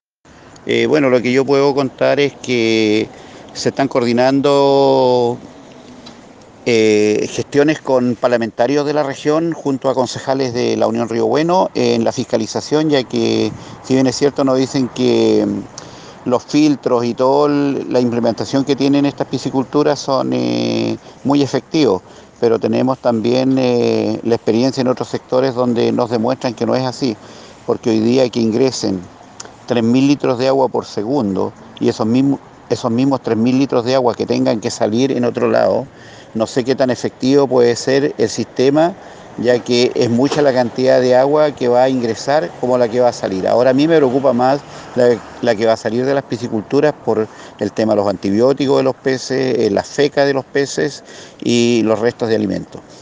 Durante la ceremonia del día mundial de los humedales actividad desarrollada en el camping municipal ubicado en el Puerto Viejo de Trumao, comuna de La Unión.